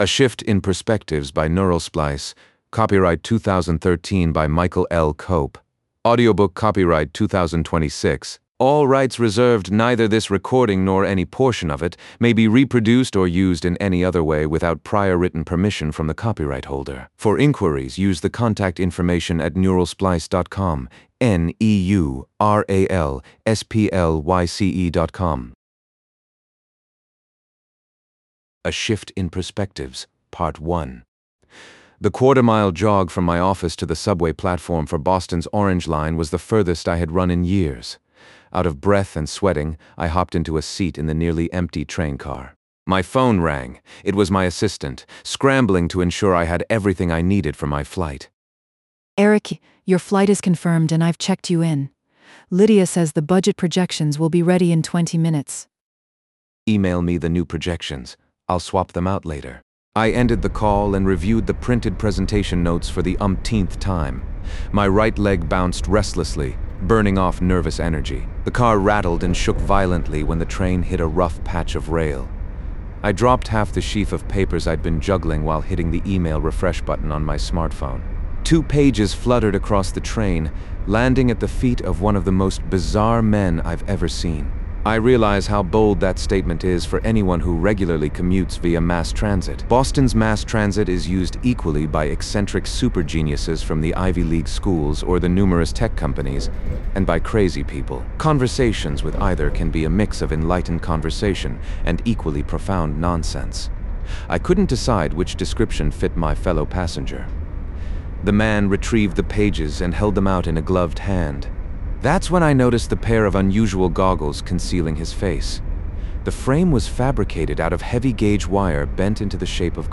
Return to Bookshelf A Shift In Perspectives Donate up to $3 Download ebook Download audiobook